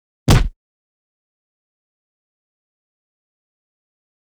赤手空拳击中肉体10-YS070524.wav
通用动作/01人物/03武术动作类/空拳打斗/赤手空拳击中肉体10-YS070524.wav
• 声道 立體聲 (2ch)